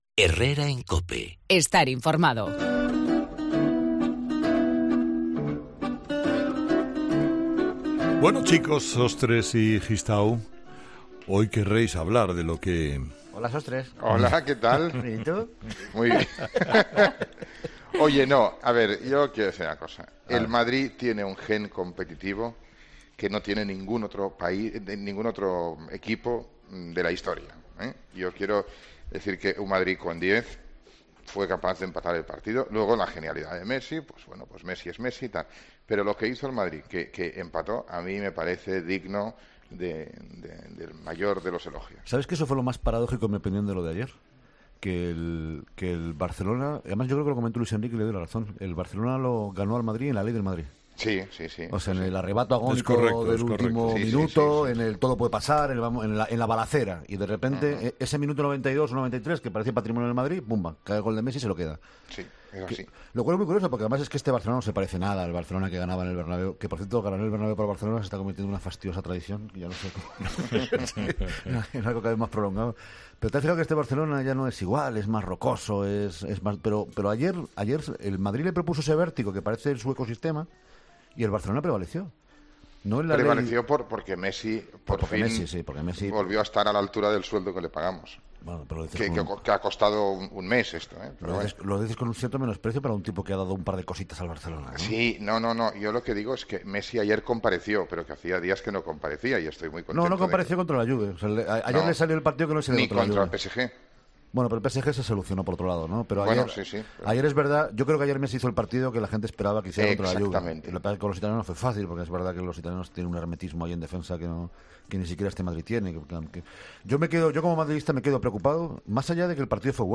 AUDIO: Salvador Sostres y David Gistau analizan el 'clásico' de este domingo